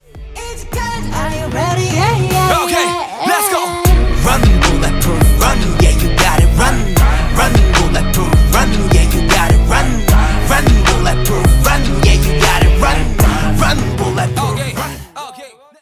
• Качество: 192, Stereo
танцевальные
kpop
корейский рэп